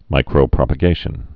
(mīkrō-prŏpə-gāshən)